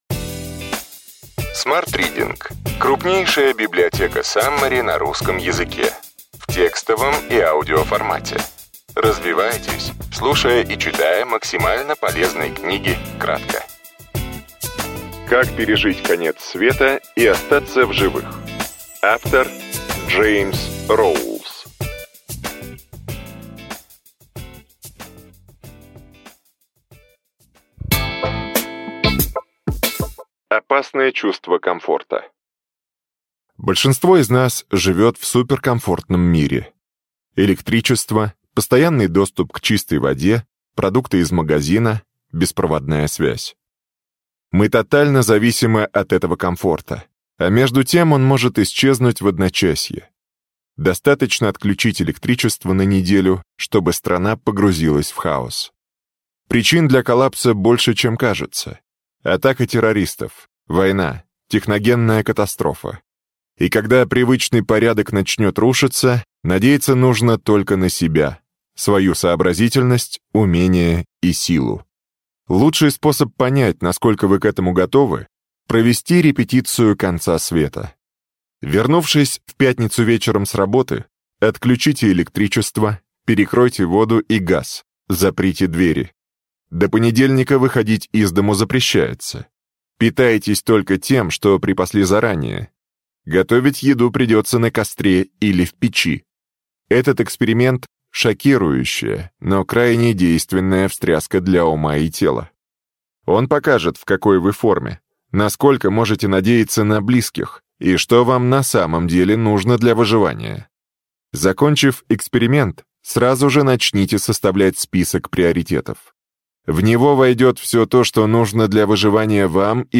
Аудиокнига Ключевые идеи книги: Как пережить конец света и остаться в живых.